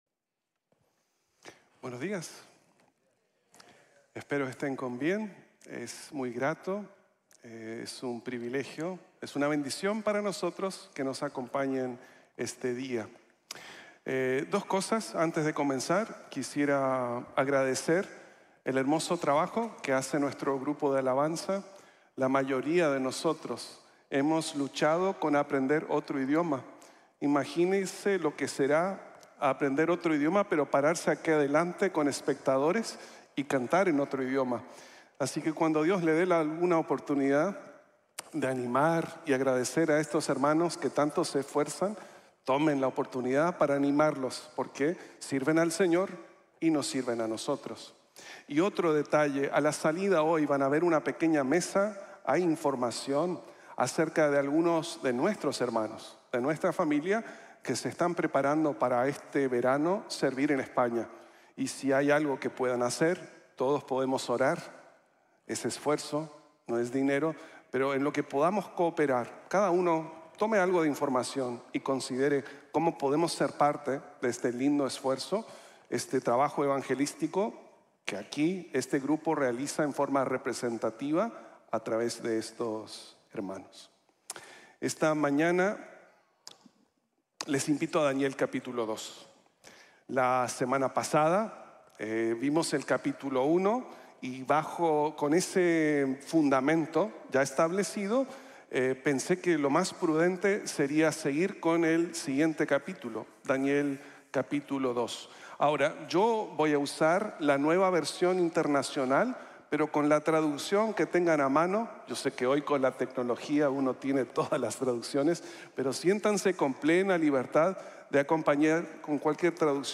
Siervo del Soberano de los Reyes | Sermon | Grace Bible Church